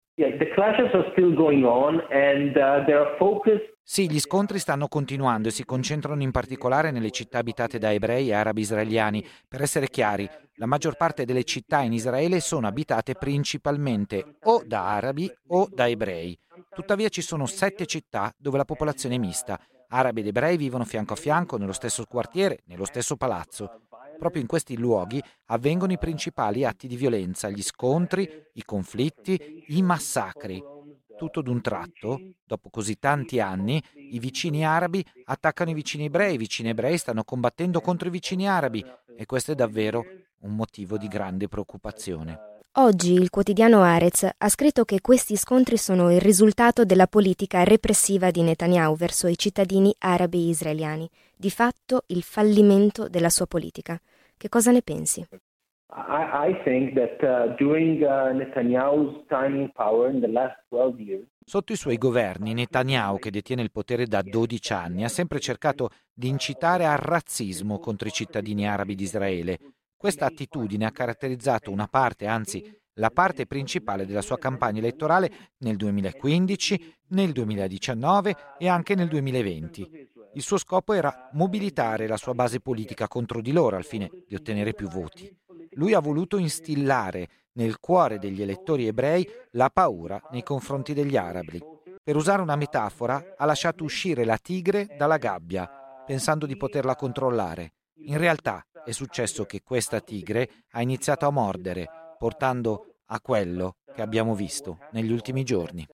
Violenze che si possono leggere anche come un’eredità dei dodici anni di potere di Benjiamin Netaniahu, come spiega il giornalista israeliano Barak Ravid: